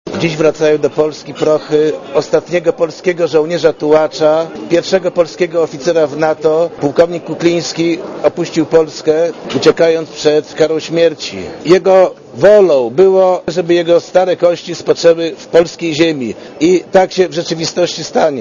Podczas krótkiej uroczystości na lotnisku Okęcie